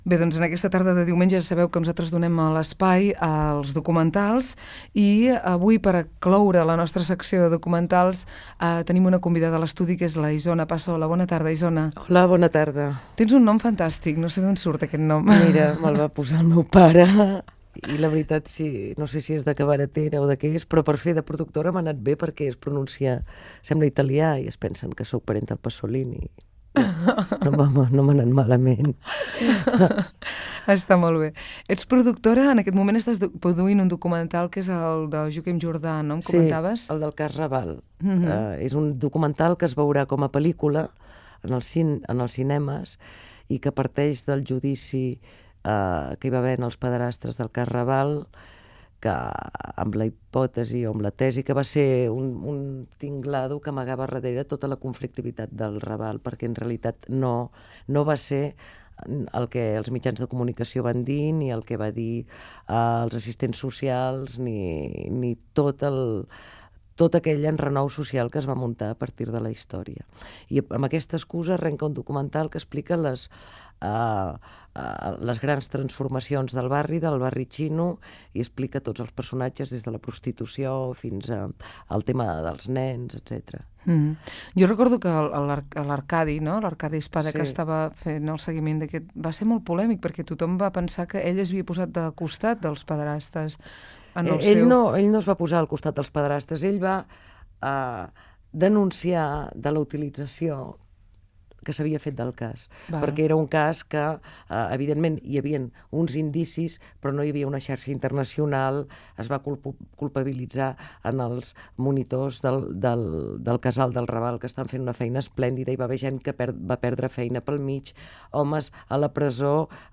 Fragment d'una entrevista
FM